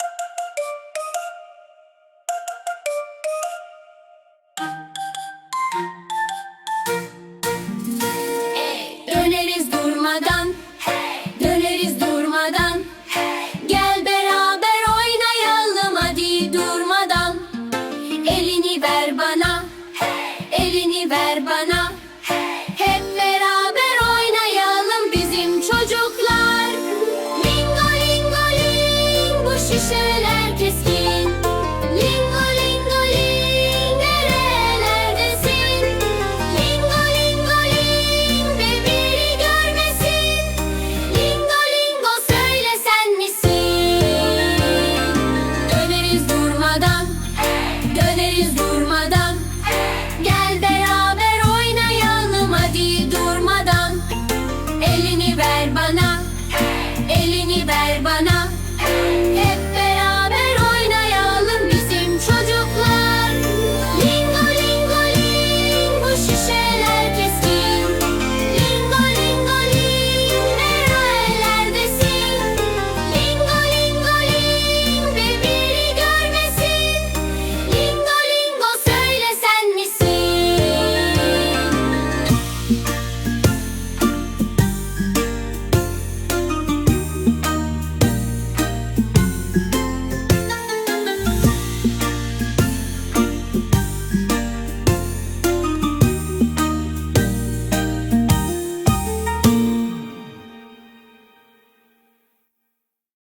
Саунтрек